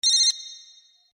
Cell Phone.mp3